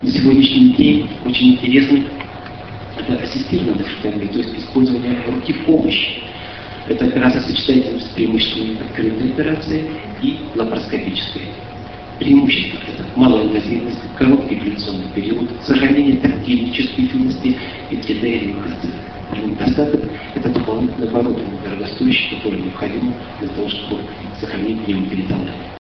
5 Российская Школа по эндоскопической и открытой урологии, 8-10 декабря 2004 года.
Лекция: ЛАПАРОСКОПИЯ В УРОЛОГИИ СЕГОДНЯ.